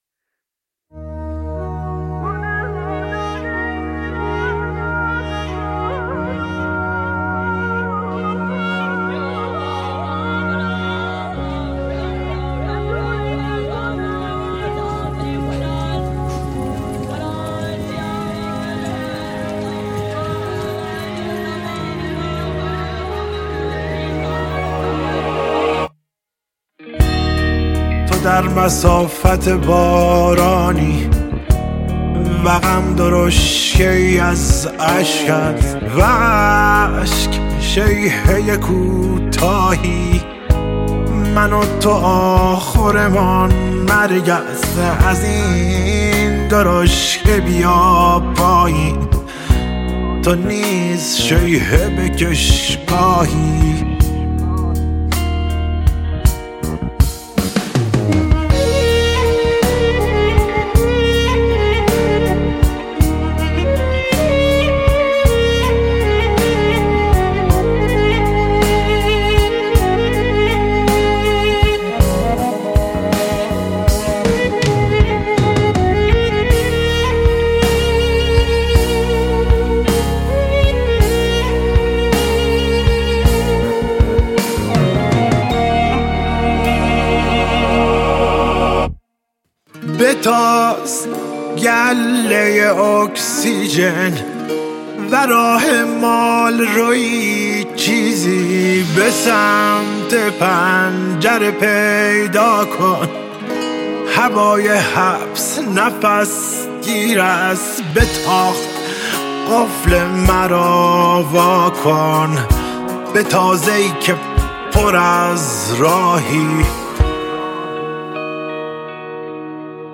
شنیدنی و احساسی